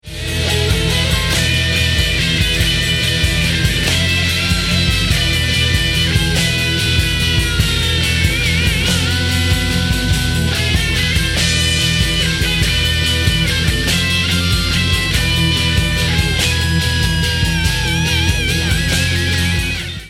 rock
stereo